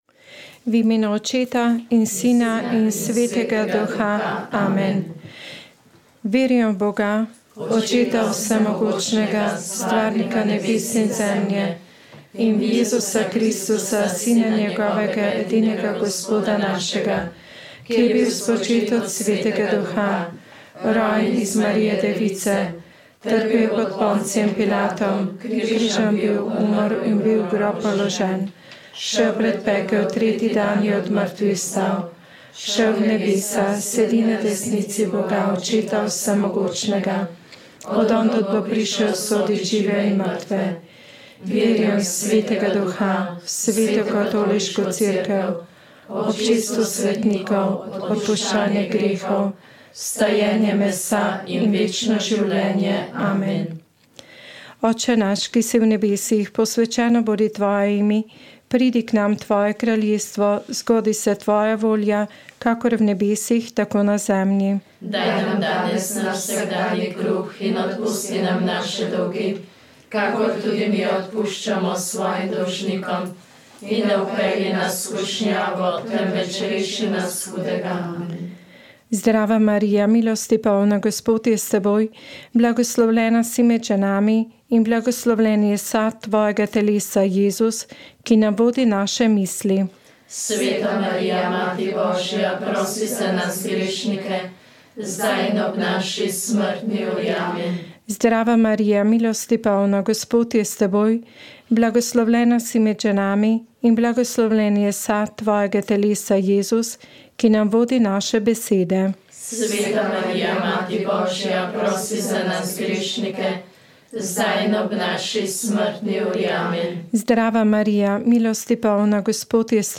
Komentar